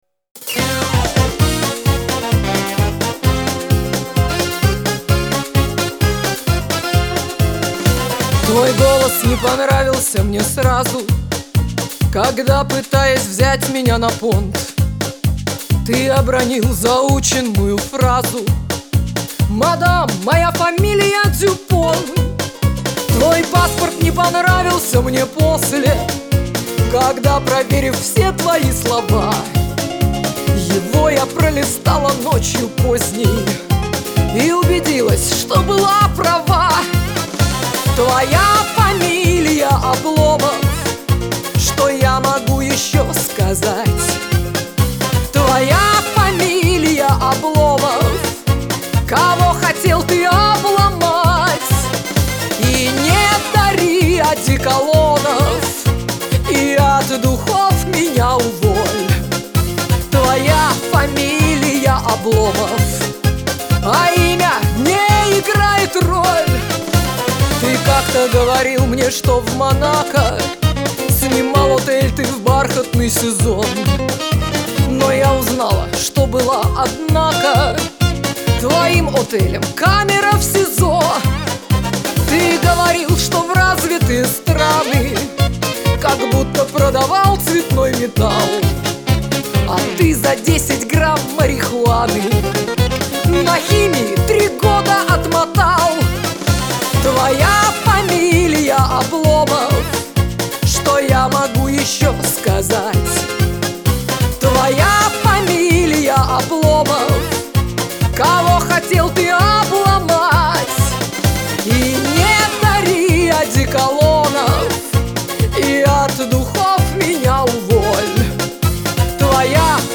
Шансон.